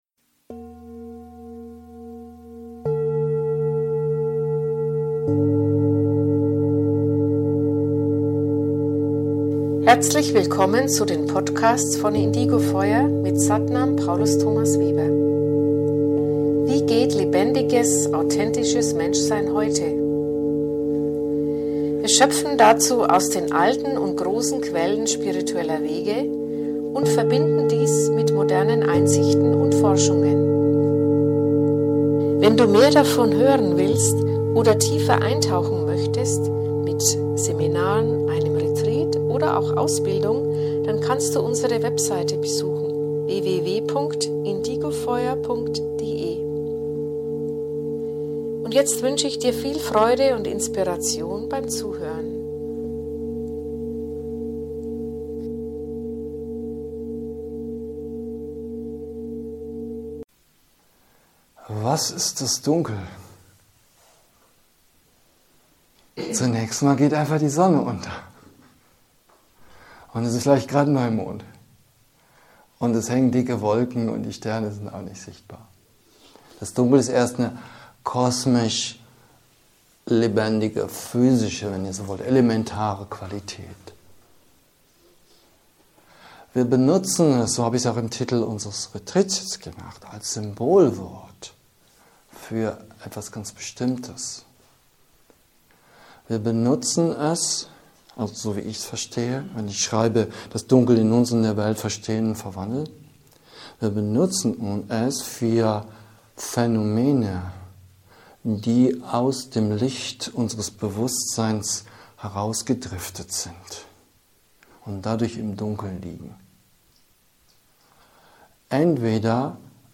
DIes ist ein Live-Mitschnitt aus einer Retreat von uns.